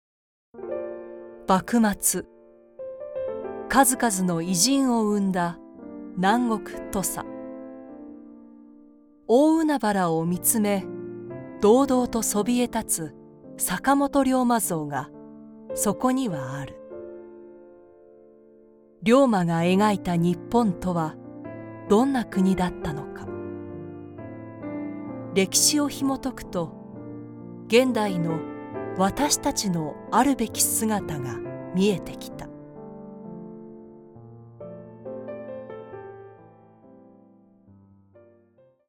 Documentales
Con mi estudio en casa equipado con equipos de primer nivel, estoy lista para darle vida a tu proyecto.
Una voz nítida que cautiva a tu audiencia
Conversacional, versátil, elegante, lujosa, auténtica, autoritaria, conversacional, profunda, confiable, optimista, amigable y creíble.
Micrófono: NEUMANN TLM102